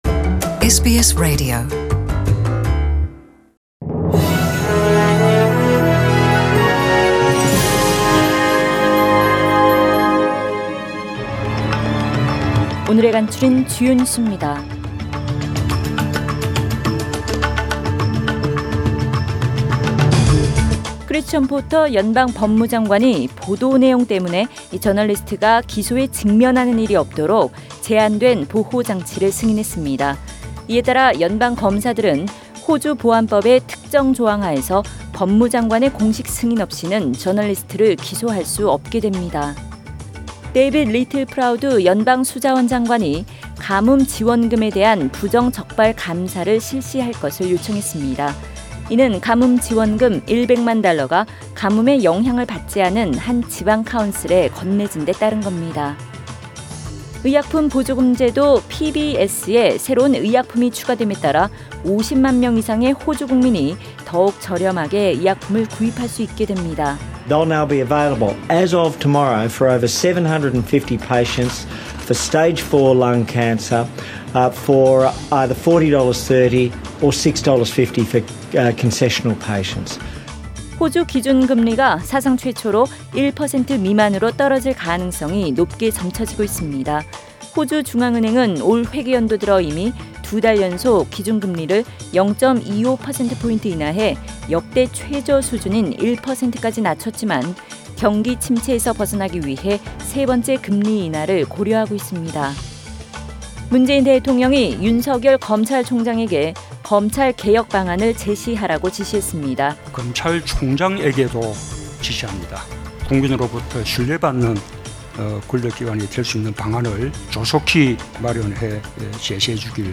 2019년 9월 30일 월요일 저녁의 SBS Radio 한국어 뉴스 간추린 주요 소식을 팟 캐스트를 통해 접하시기 바랍니다.